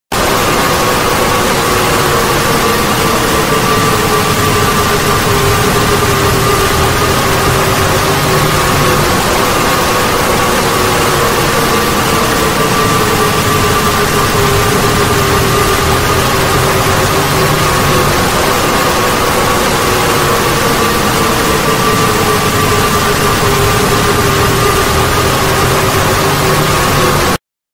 15:27, 3 September 2022 Ambush Static Noise.mp3 (
Ambush_Static_Noise.mp3